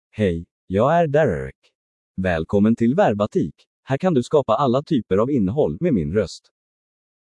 MaleSwedish (Sweden)
DerekMale Swedish AI voice
Derek is a male AI voice for Swedish (Sweden).
Voice sample
Derek delivers clear pronunciation with authentic Sweden Swedish intonation, making your content sound professionally produced.